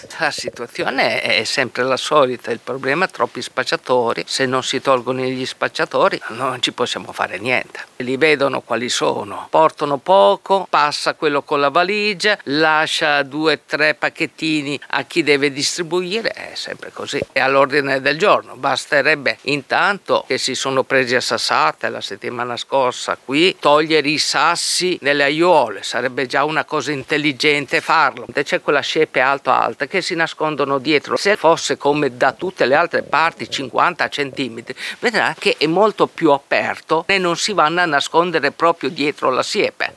Zona Tempio: parla un commerciante
Uno storico commerciante della zona tempio: